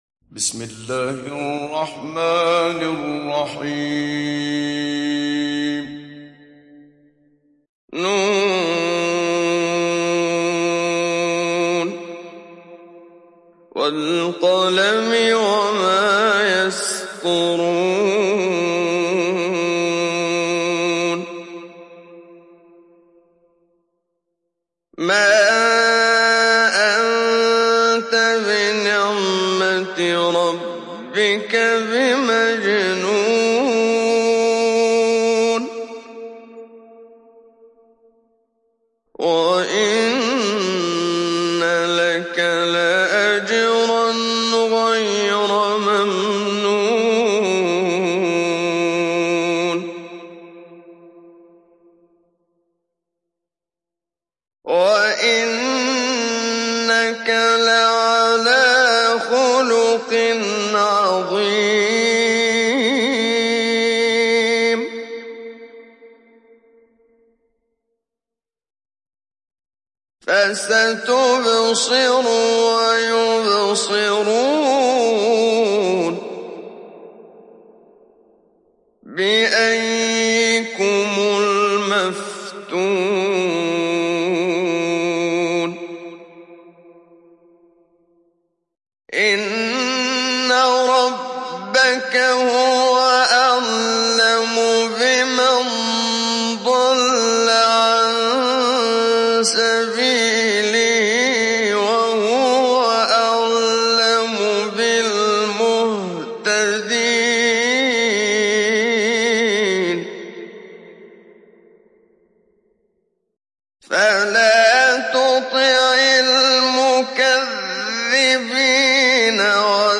ডাউনলোড সূরা আল-ক্বালাম Muhammad Siddiq Minshawi Mujawwad